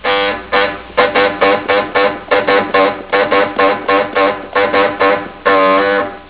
Bocina
Robé una bocina en forma de bulbo de un taxi y me la puse bajo el cinturón para mi papel en "Home Again".
¿Quieres escuchar mi bocina?